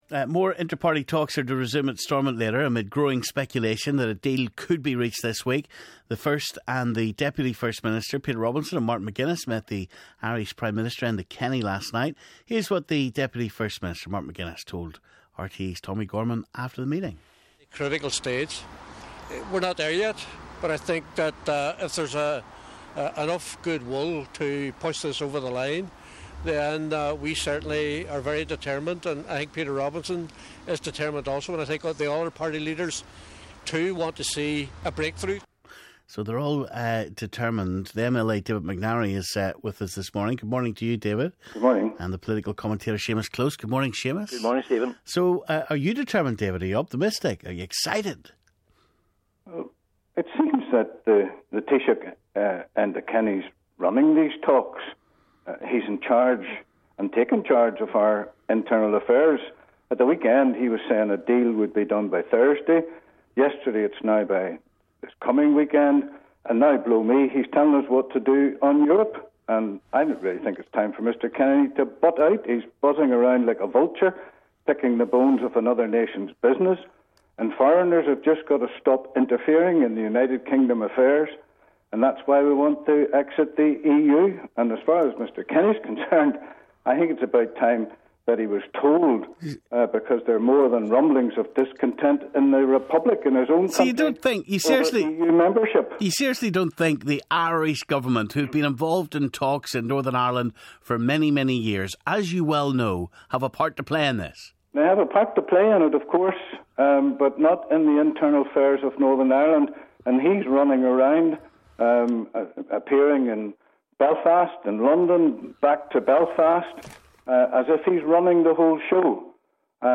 Political commentator Seamus Close and UKIP MLA David McNarry joined us this morning